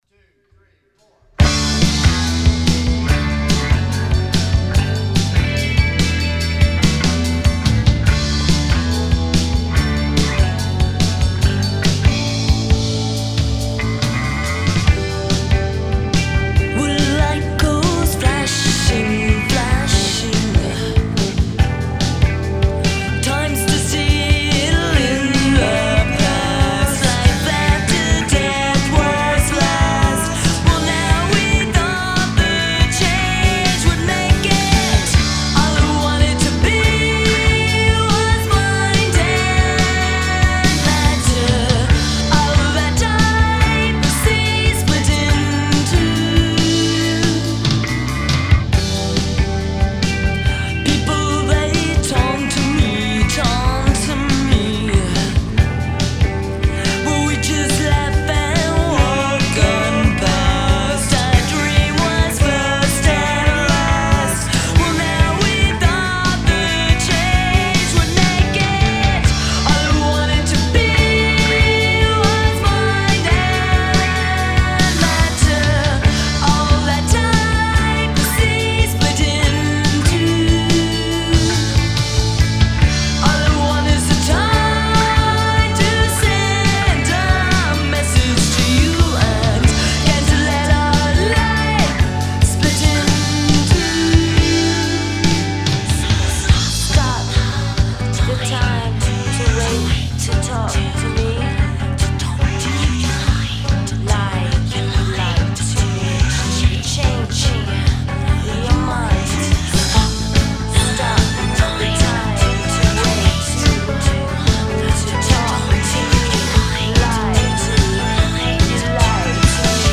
Some of the earliest home recording attempts
NEW rough mixes from baked original 8 track tape: